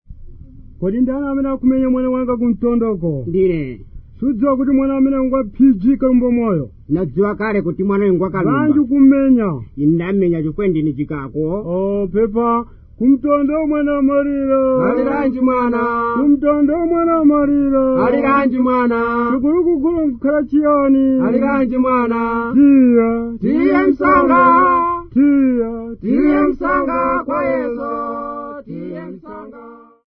Two Chewa men
Folk music--Africa
Field recordings
Africa Zambia Kota Kota f-za
A topical song about the sound of crying.